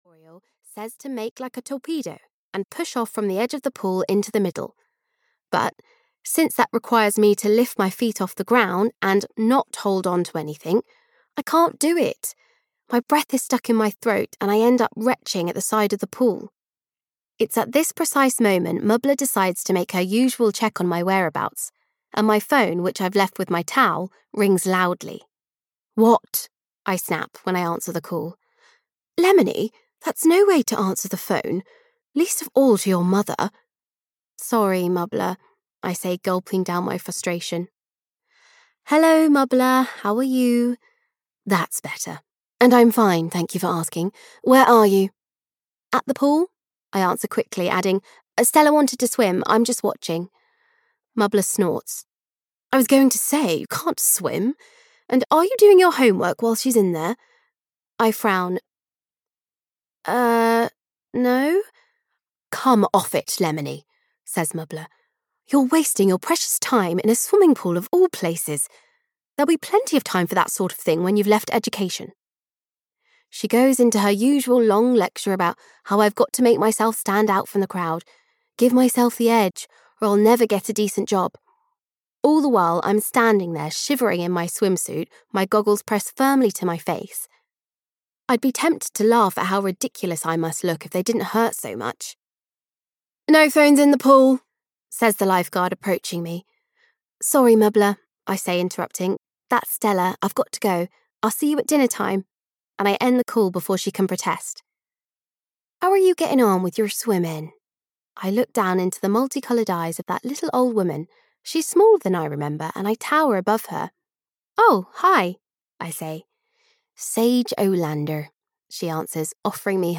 Fig Swims the World (EN) audiokniha
Ukázka z knihy